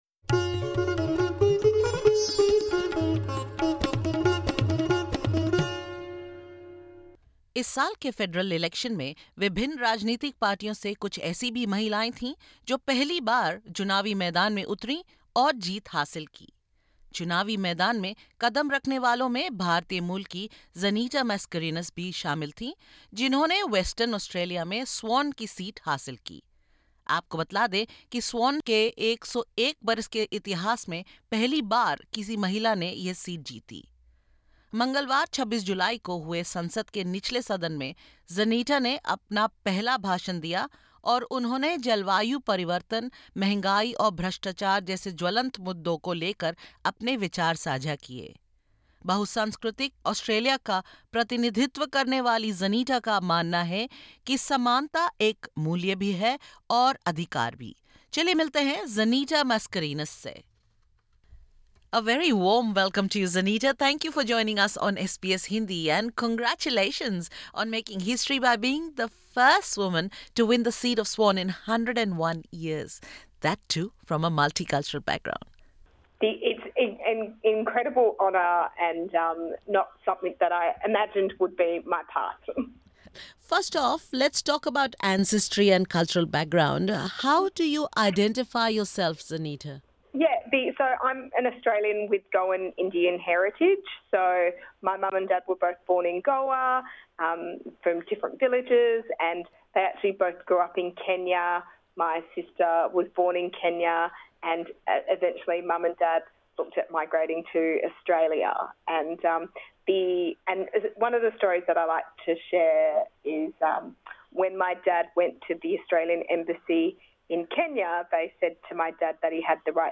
Labor MP Zaneta Mascarenhas, who delivered her maiden speech to parliament recently, talks to SBS Hindi about her Indian heritage, climate change and leadership in the workplace.